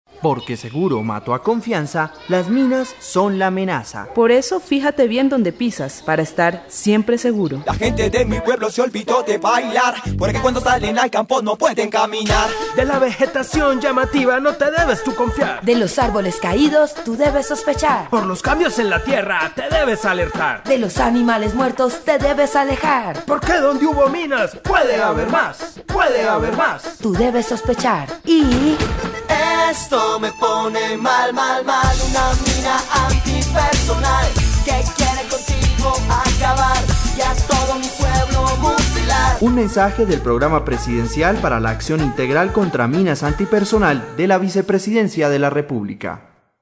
La campaña incluye siete comerciales de televisión sobre los riesgos y las orientaciones para asumir comportamientos seguros; cinco cuñas radiales con los mensajes básicos de prevención y las voces de importantes artistas como Maia y el grupo San Alejo, entre otros.
Cuñas radiales